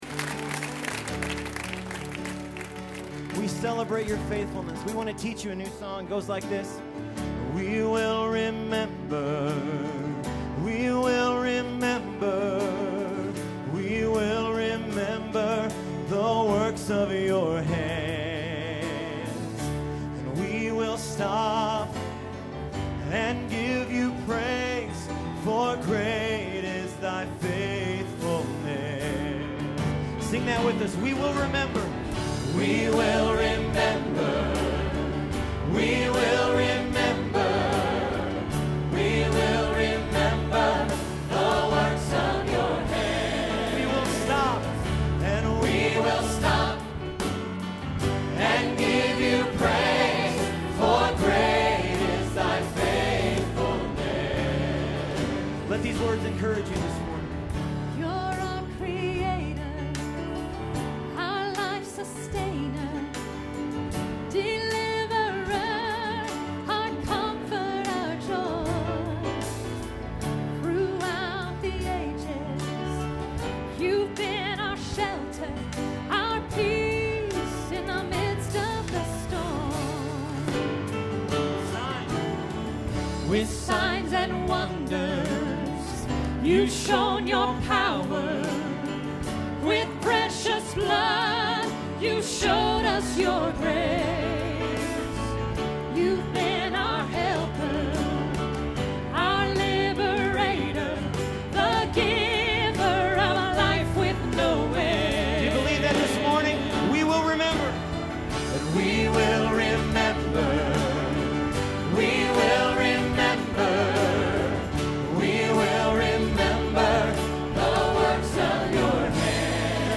This past Sunday was a real celebration of God’s faithfulness.
Here is the audio of us doing “We Will Remember” from yesterday.